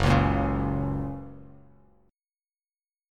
F# Chord
Listen to F# strummed